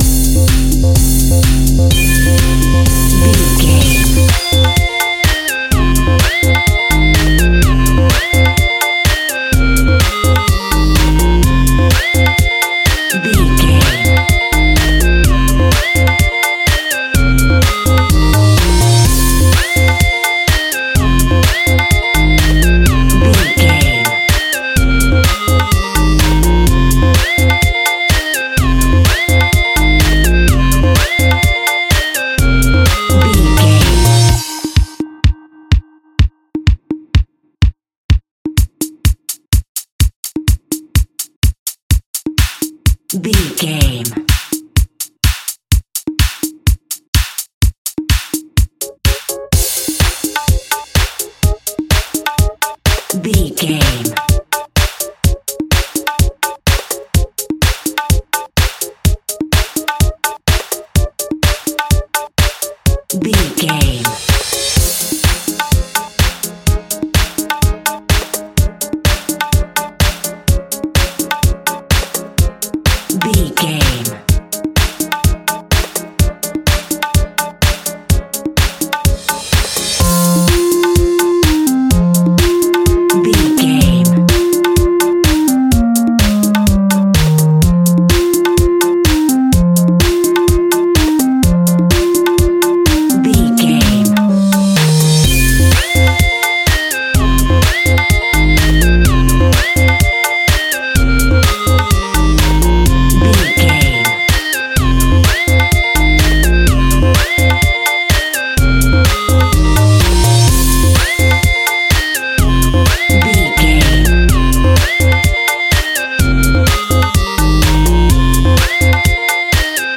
Aeolian/Minor
intense
energetic
repetitive
drum machine
synthesiser
funky house instrumentals
synth bass
funky synths
guitar